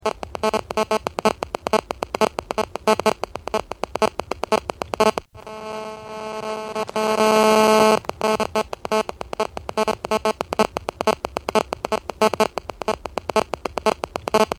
Interference phone - Помехи от телефона
Отличного качества, без посторонних шумов.
357_pomehi-telefona.mp3